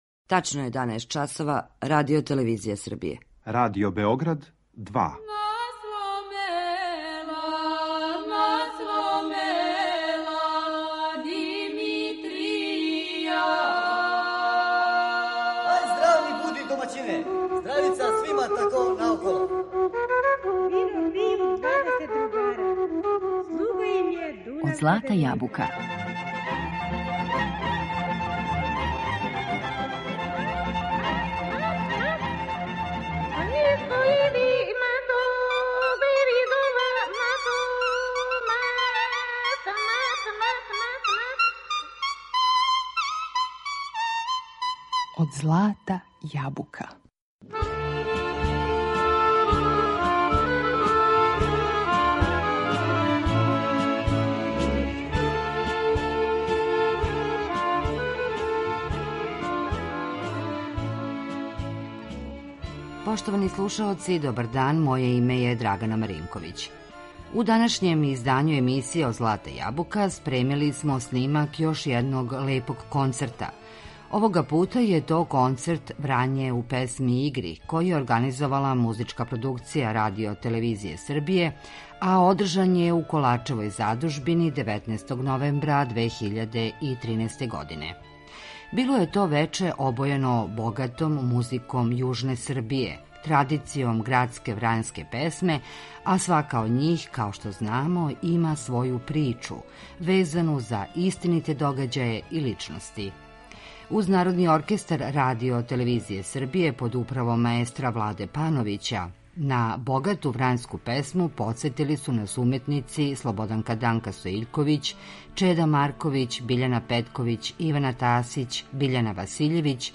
Снимак концерта „Врање у песми и игри"
Био је то концерт обојен богатом музиком јужне Србије, традицијом градске врањске песме, а свака од њих има своју причу везану за истините догађаје и личности.
Програм је водио глумац Небојша Дугалић.